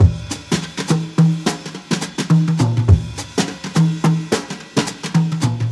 • 83 Bpm Jazz Drum Loop F Key.wav
Free breakbeat sample - kick tuned to the F note. Loudest frequency: 1282Hz
83-bpm-jazz-drum-loop-f-key-FBC.wav